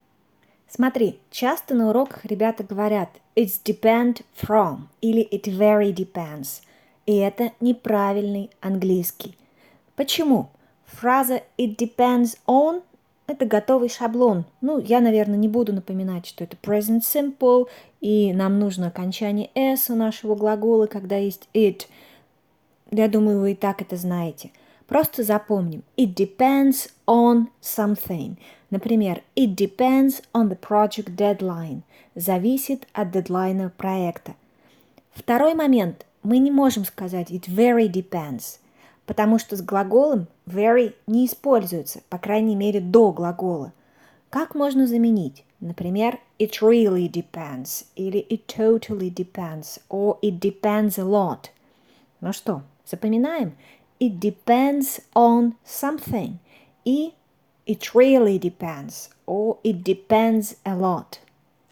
Впервые записала для вас голосовое, где по горячим следам хочу рассказать, почему **"it’s depend from"** и **"it’s very depend"** — не лучшие паттерны для вашего английского.🙅🏻‍♀
Как вам новый формат аудио-объяснений?